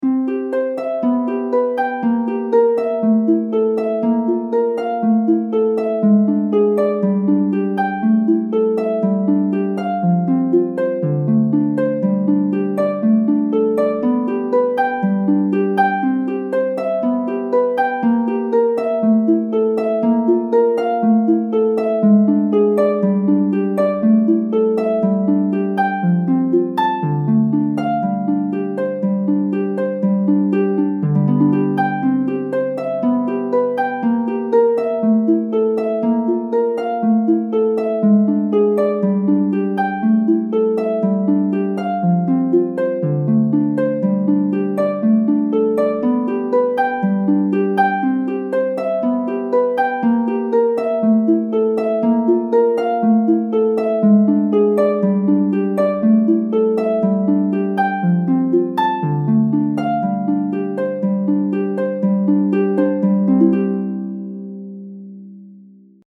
Harp.mp3